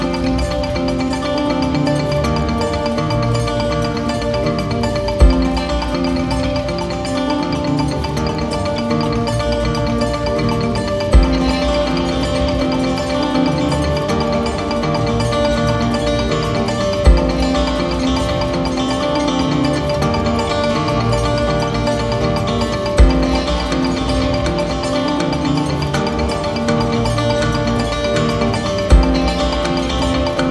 électro maloya